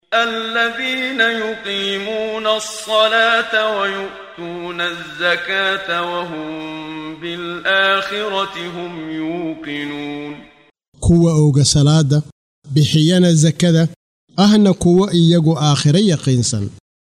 قراءة صوتية باللغة الصومالية لمعاني سورة السجدة مقسمة بالآيات، مصحوبة بتلاوة القارئ محمد صديق المنشاوي - رحمه الله -.